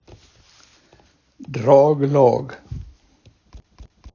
Så här kan draglag användas i en mening